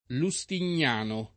[ lu S tin’n’ # no ]